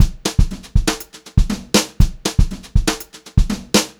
120HRBEAT3-L.wav